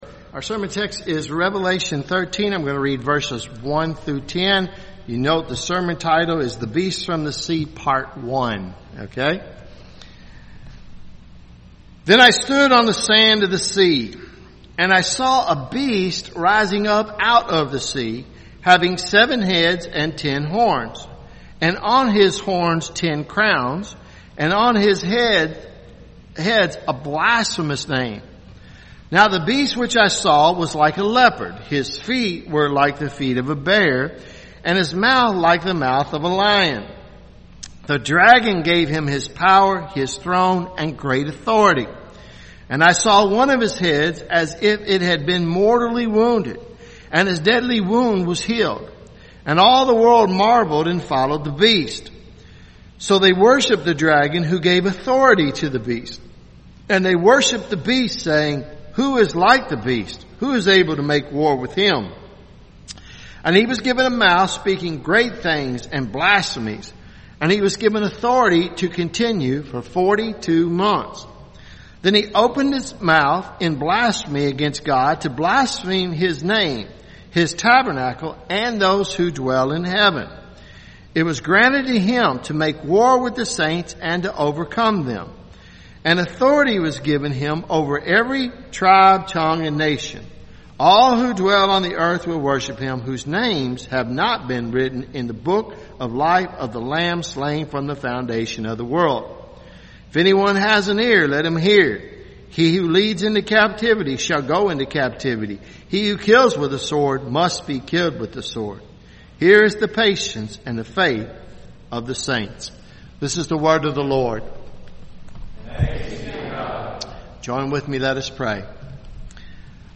Revelation sermon series , Sermons